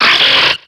Cri de Ténéfix dans Pokémon X et Y.